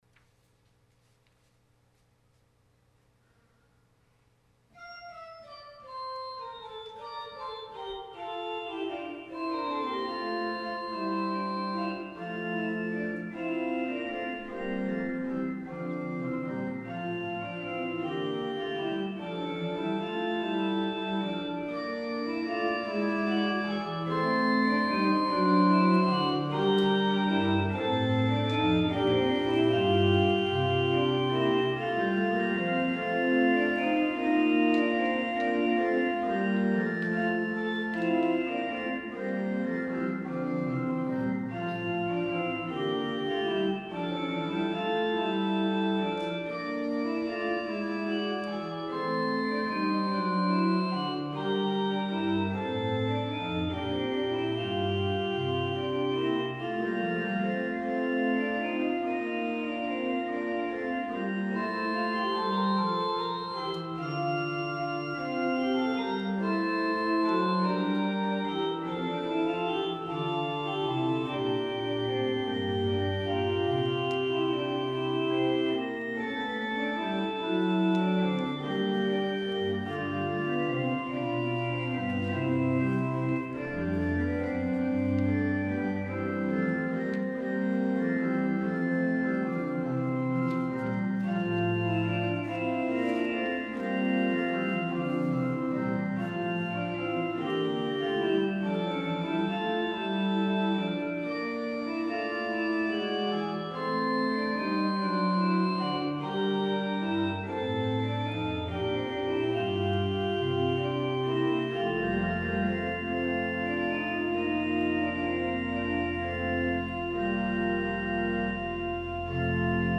Sunday Worship 4-19-20 (Second Sunday of Easter)